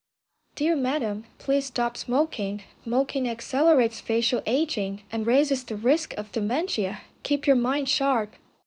50-59 female.wav